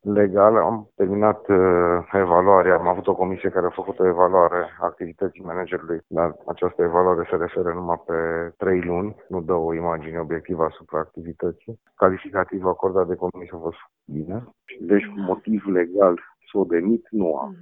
Primarul Sighişoarei, Ovidiu Mălăncrăvean, a declarat pentru RTM că s-a constituit o comisie care să analizeze situaţia spitalului, dar nu s-au găsit nereguli care să poată desfiinţa contractul de management: